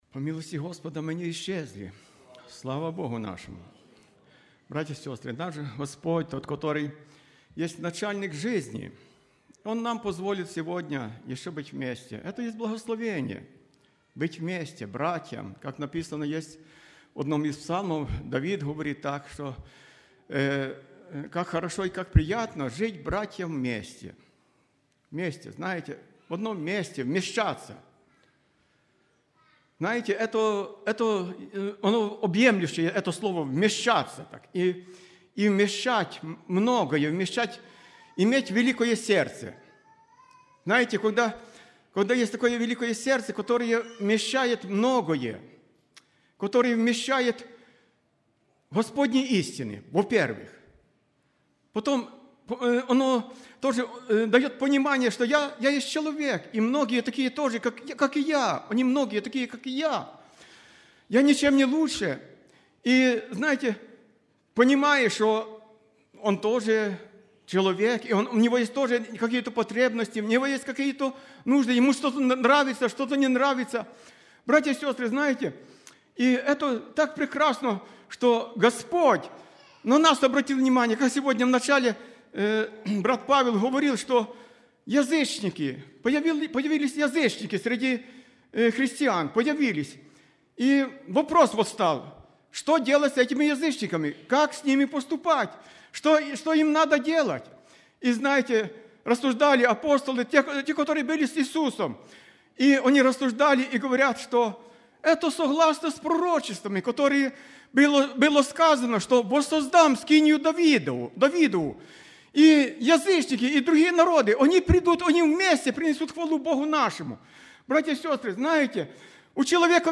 10-06-24 Воскресение — Церковь «Путь ко Спасению»
06+Проповедь.mp3